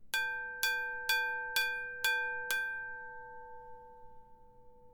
Glass hammer on a crystal bell
bell clang crystal ding dinging glass hammer ring sound effect free sound royalty free Sound Effects